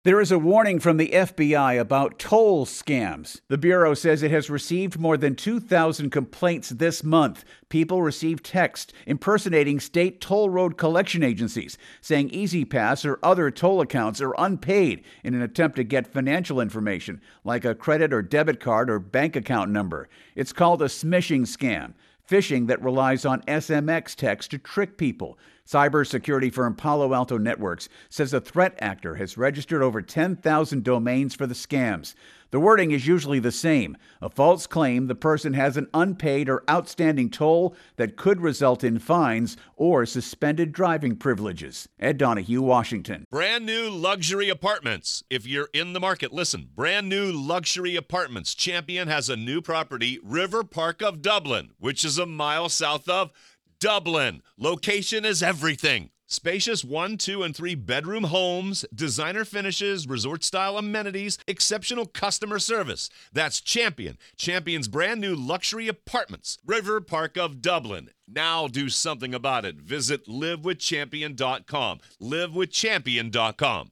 reports on a scame targeting drivers.